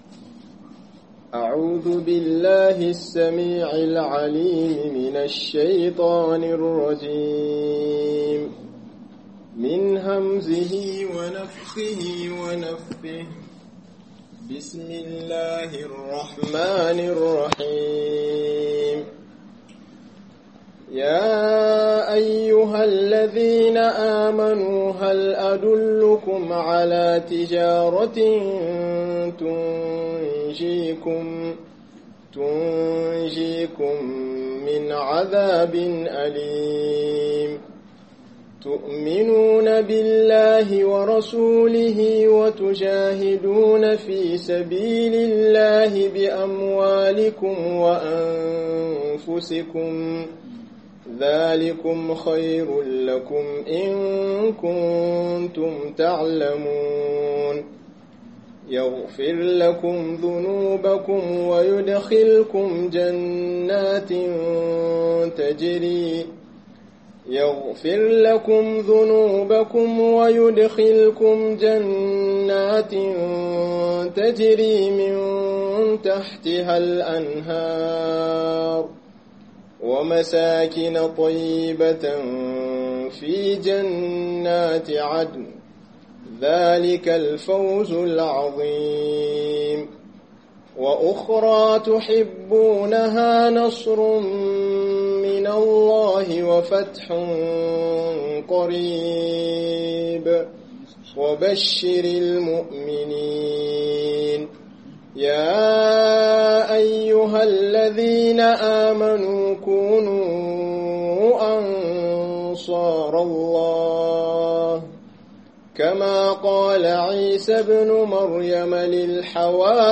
Soyayyar Annabi (S.A.W) - Muhadara